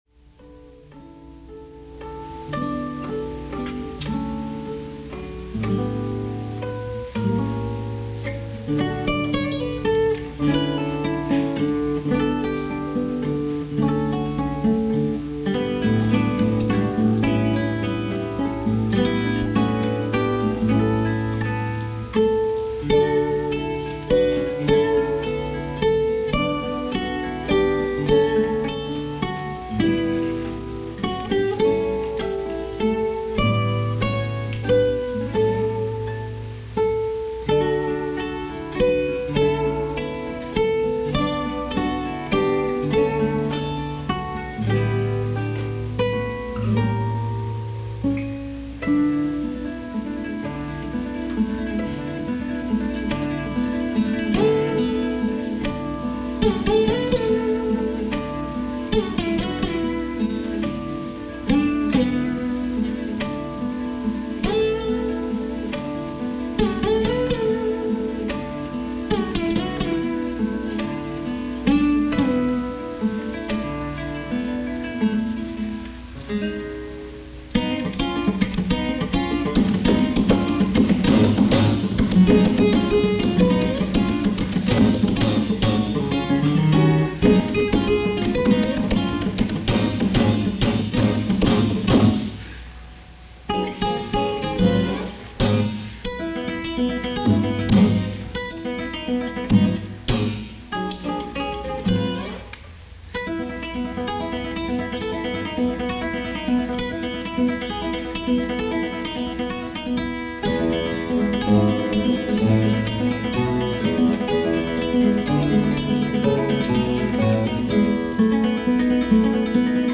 Each of these little fragments I quilted together into a pastiche that became “Belladonna” for guitar trio. For my senior recital the Madison Guitar Trio played Belladonna, and I have a surviving recording of the event. Even with the low quality of the recording, the magic still effervesces from the performance and the audience audibly responds with gasps and laughter to some of the musical twists and turns.